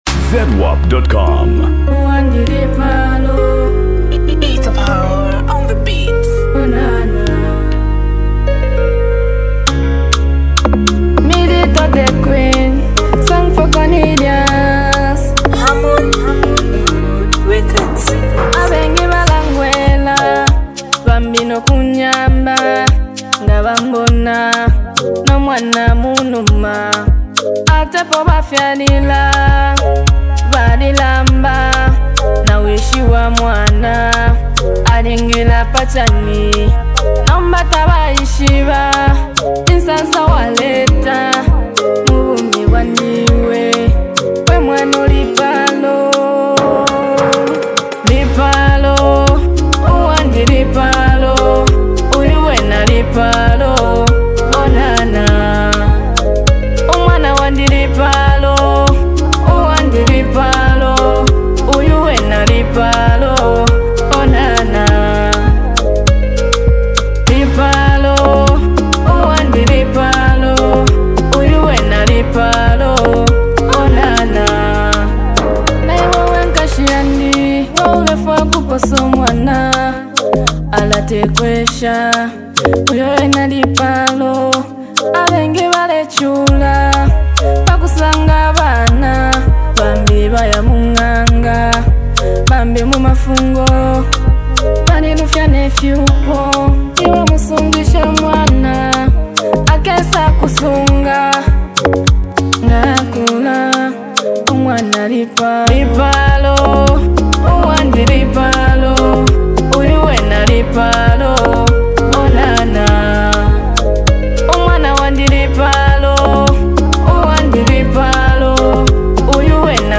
amazing touching and emotional song